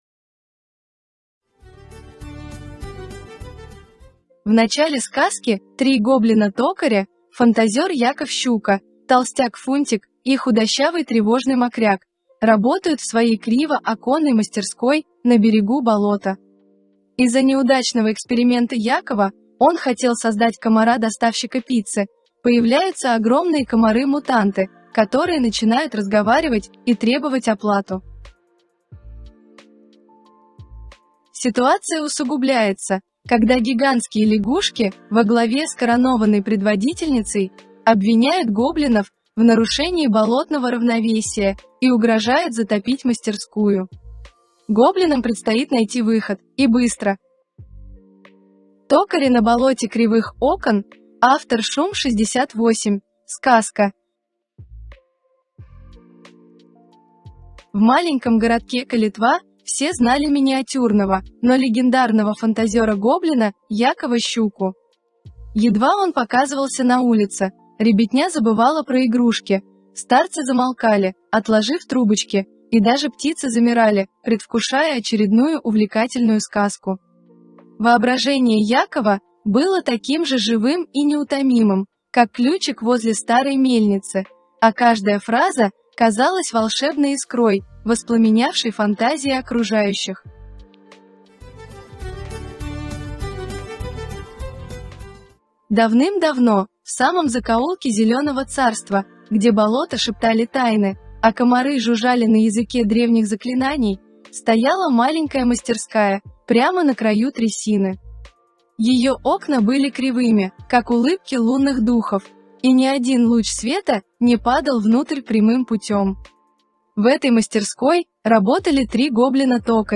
Сказка